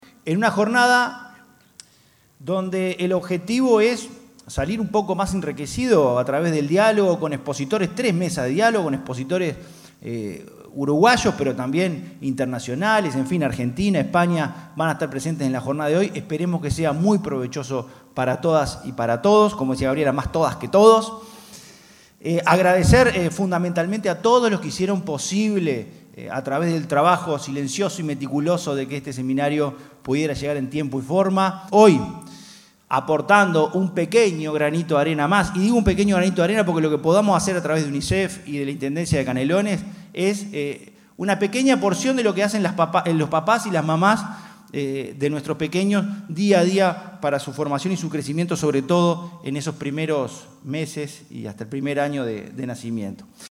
francisco_legnani_secretario_general_0.mp3